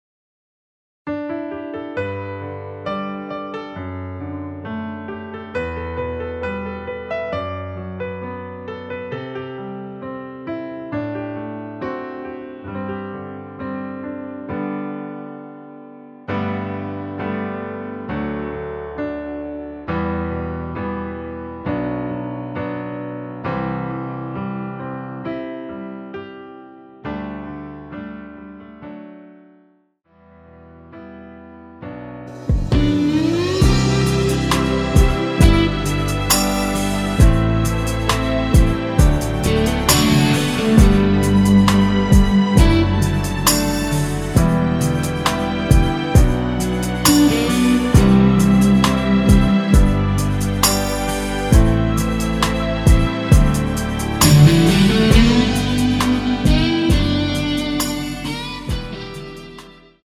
엔딩이 페이드 아웃이라 노래 하시기 좋게 엔딩을 만들어 놓았습니다.
앞부분30초, 뒷부분30초씩 편집해서 올려 드리고 있습니다.
중간에 음이 끈어지고 다시 나오는 이유는